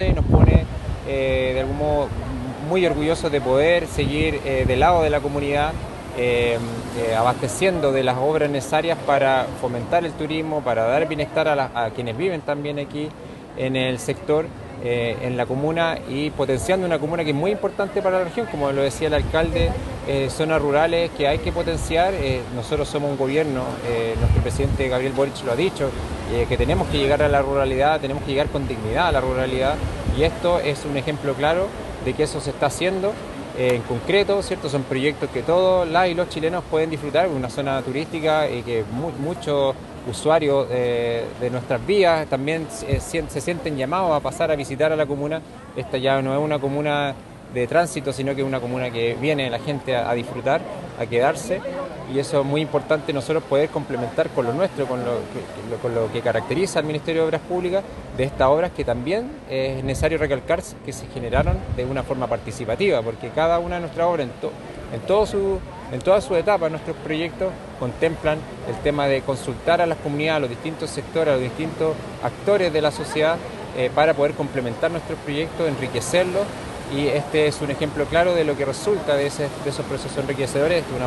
Respecto a la entrega de la obra, el SEREMI de Obras Públicas, Javier Sandoval, indicó que
SEREMI-MOP-Javier-Sandoval.m4a